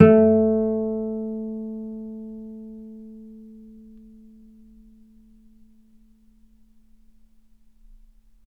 vc_pz-A3-mf.AIF